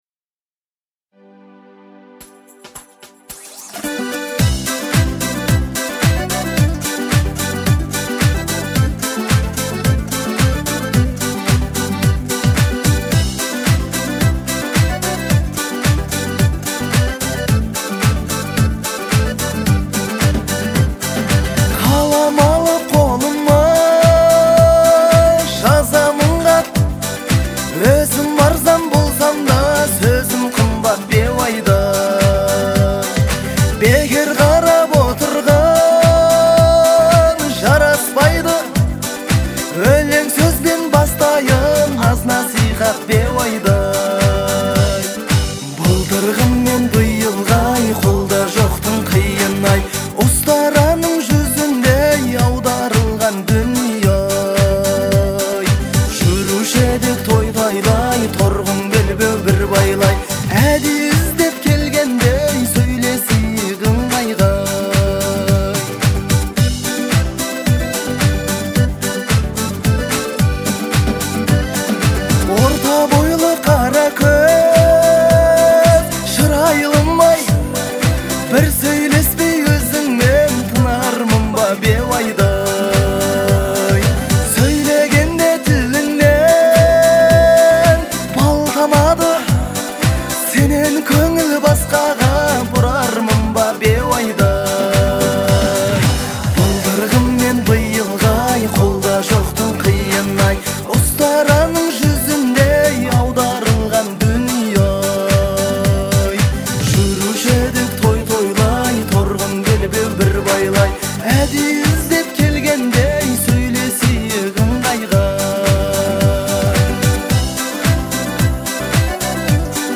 это традиционная казахская народная песня